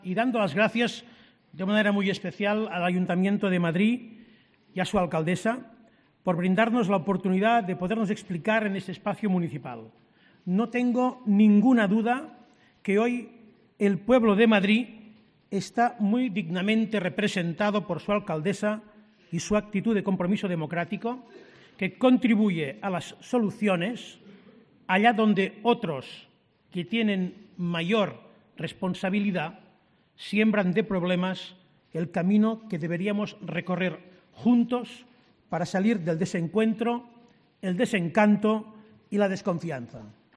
Puigdemont ha aprovechado su intervención en el Auditorio Caja de Música CentroCentro, en el madrileño Palacio de Cibeles, en el acto "Un referéndum para Cataluña. Invitación a un acuerdo democrático" para dejar claras sus intenciones: habrá referéndum y, como lo habrá, ha pedido al Gobierno dialogar desde ya sobre la pregunta de la consulta, los requisitos o el método de validación del resultado.